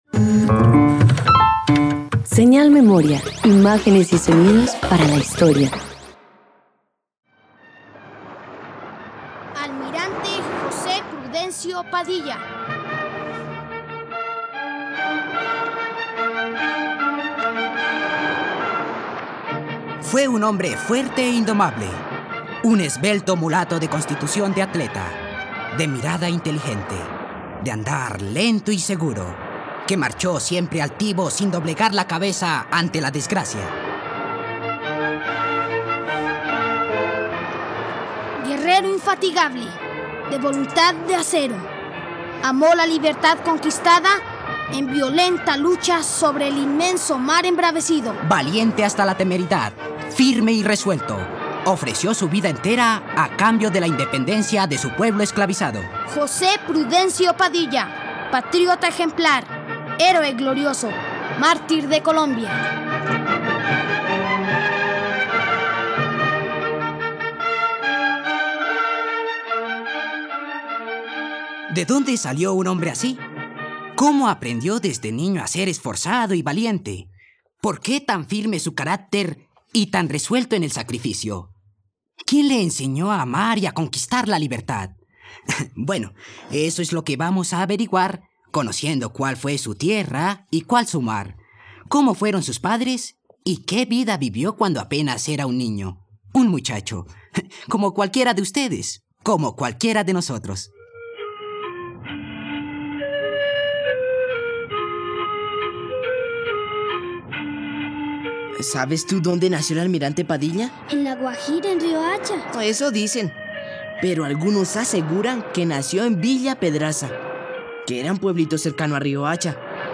Almirante José Prudencio Padilla - Radioteatro dominical | RTVCPlay